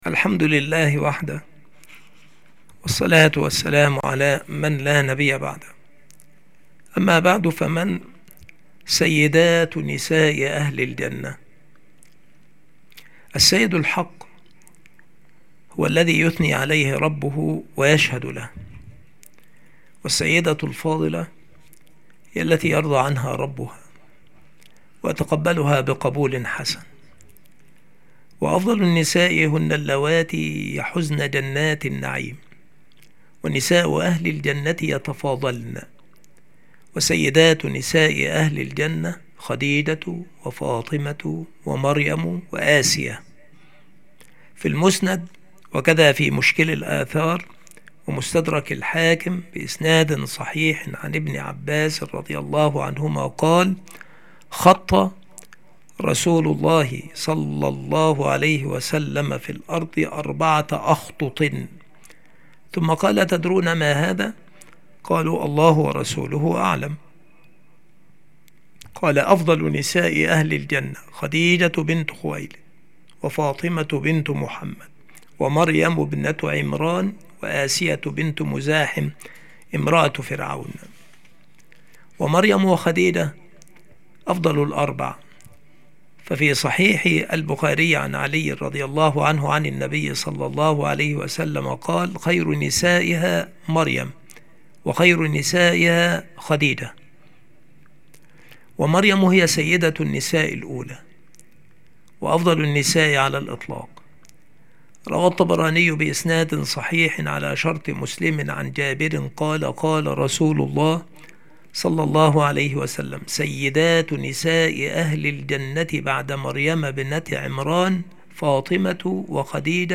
التصنيف عقيدتنا الإسلامية [ مقاطع موجزة ]
مكان إلقاء هذه المحاضرة المكتبة - سبك الأحد - أشمون - محافظة المنوفية - مصر